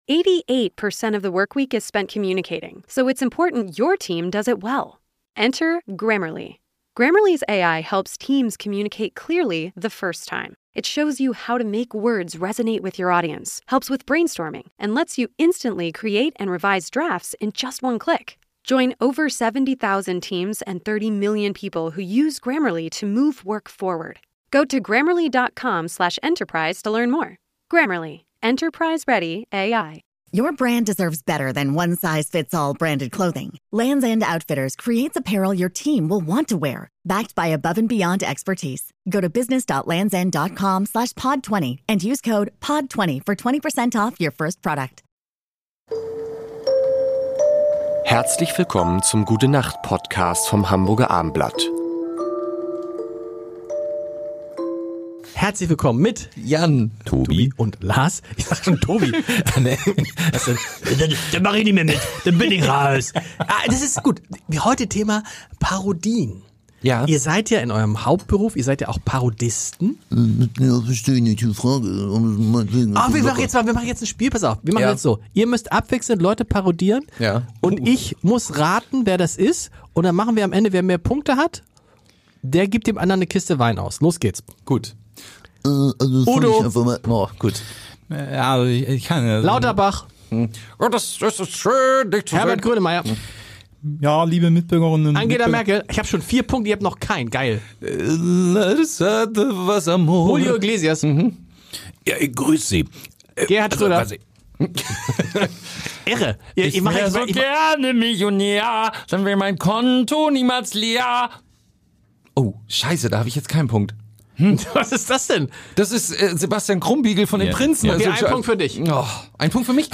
Das große Promistimmen-Raten